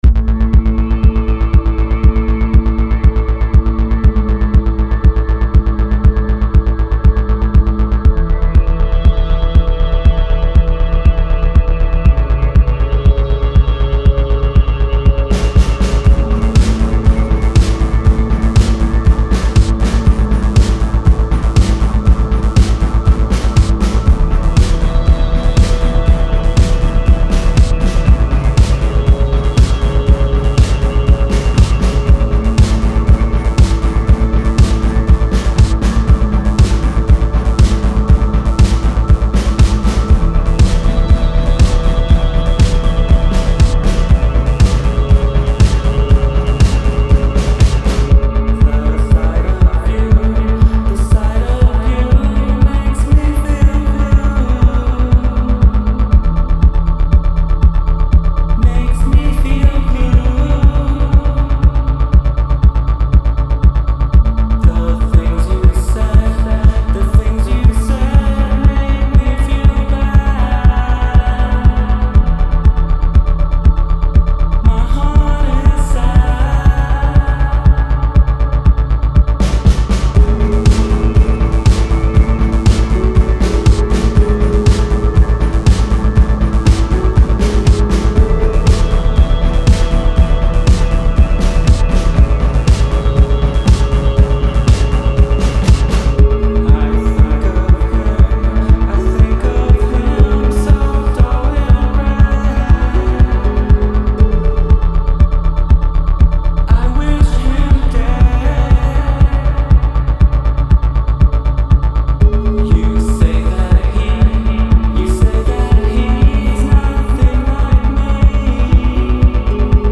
I went into the studio.